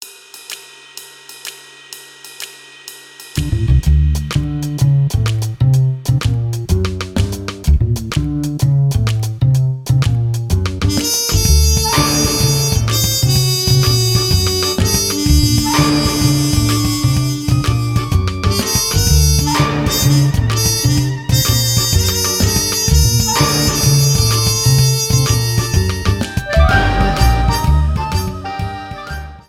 Ripped from the game
faded out the last two seconds